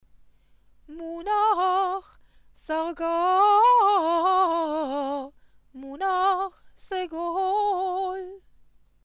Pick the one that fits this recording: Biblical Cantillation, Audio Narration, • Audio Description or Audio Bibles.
Biblical Cantillation